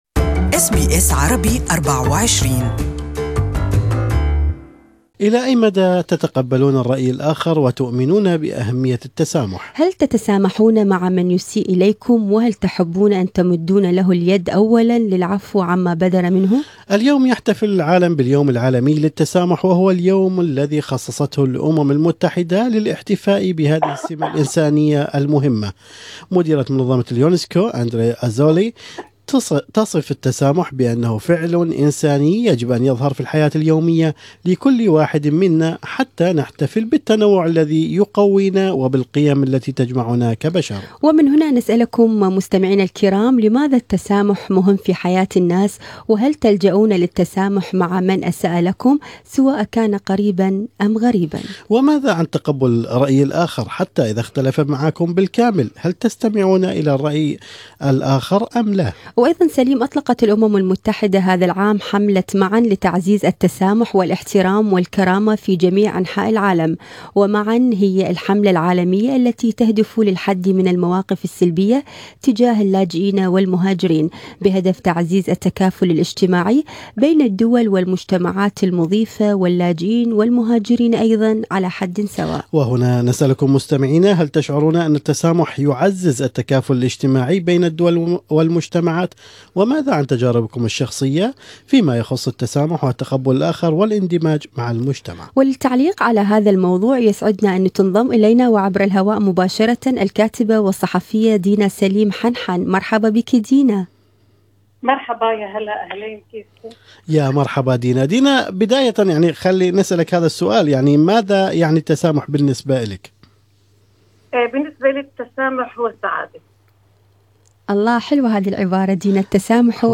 وللتعليق على هذا الموضوع كان لنا هذا اللقاء مع الكاتبة والصحفية